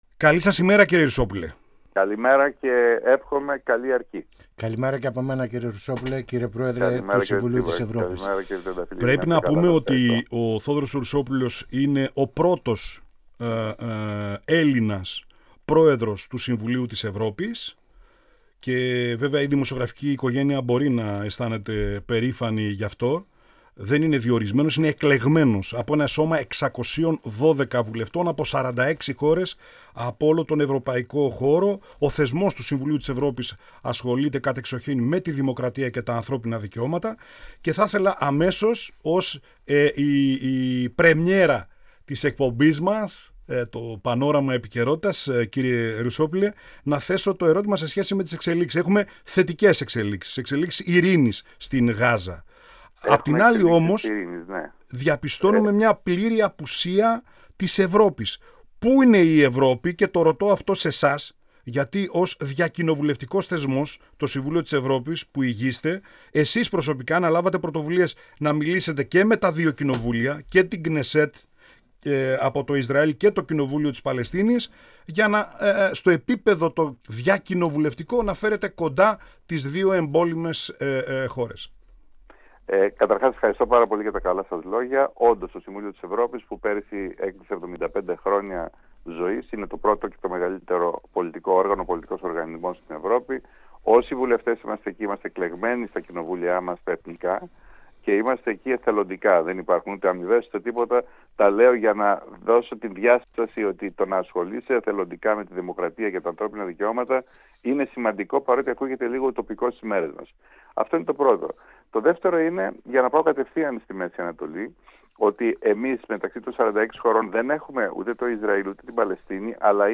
Ο κ. Ρουσόπουλος μιλώντας στην εκπομπή «Πανόραμα Επικαιρότητας» του 102FM της ΕΡΤ3, εγκαινίασε την πρώτη εκπομπή με την πρώτη του συνέντευξη σε αυτήν.